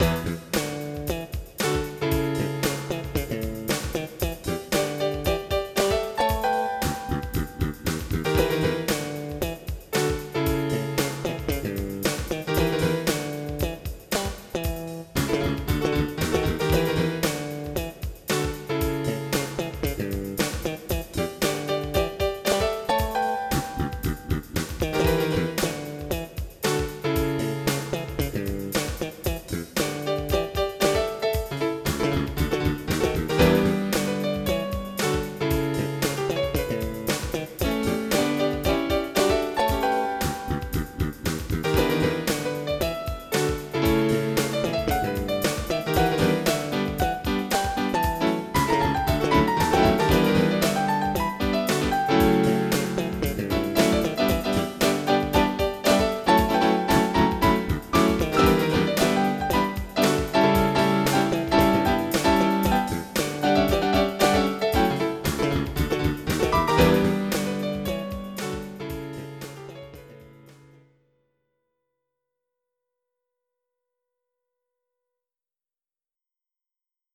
MIDI Music File
Type General MIDI
funk.mp3